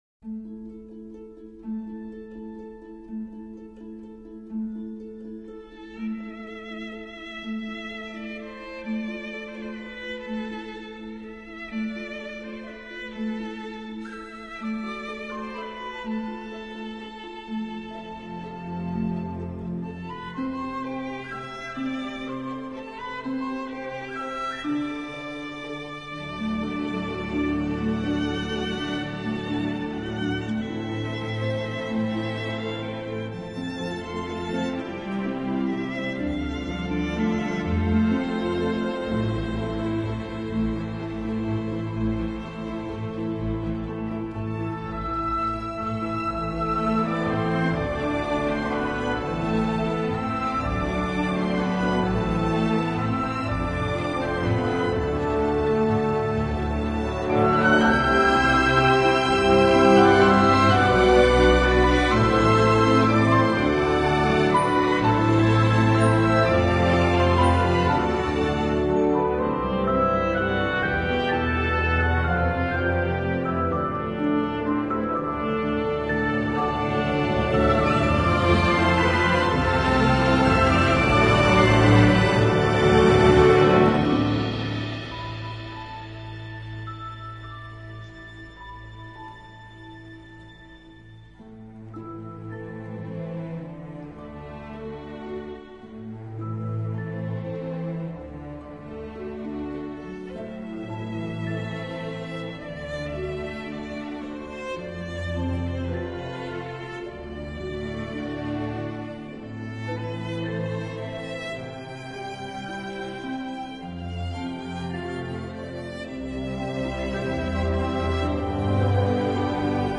以小提琴独奏开场，逐渐加入大弦乐，音乐稍微带一点爱尔兰民乐风味
整篇配乐也都有这个特点，弦乐中透出点诗歌的节奏韵味，不会像单纯的古典乐那样乏味，但是可惜少了一首主题歌。